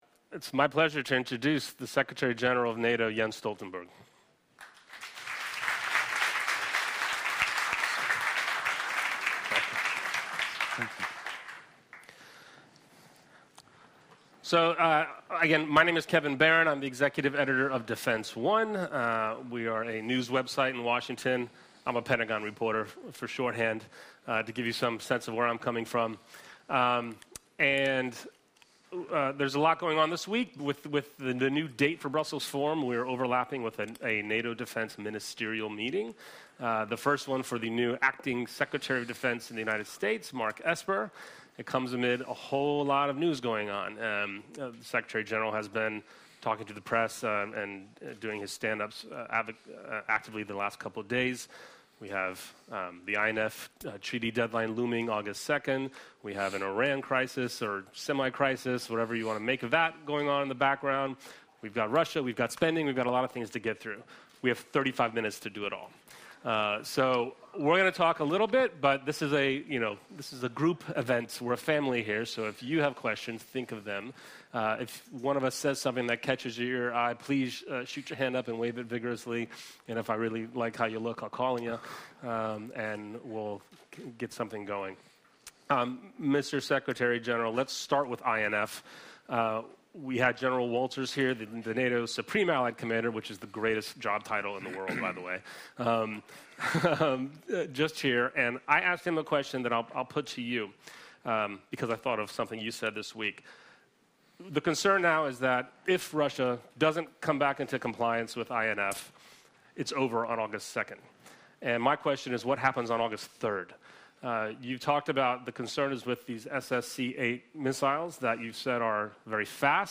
Conversation with NATO Secretary General Jens Stoltenberg at the Brussels Forum